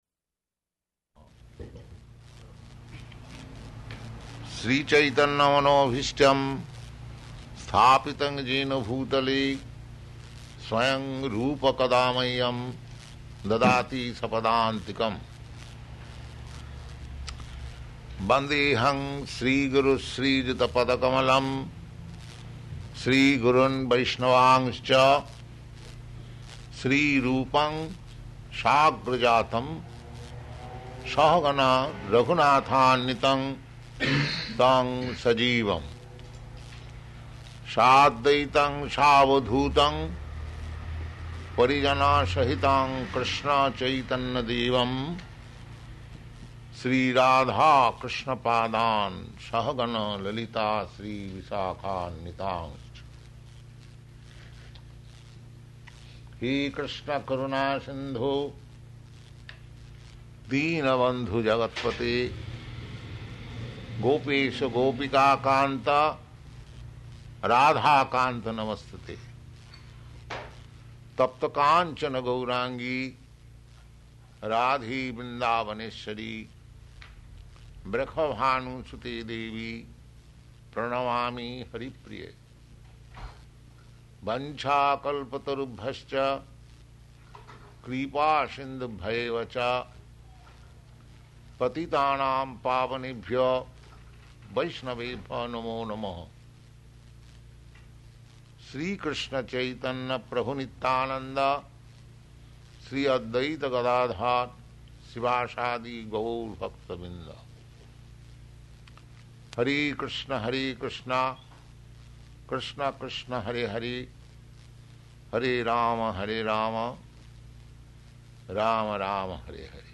Location: London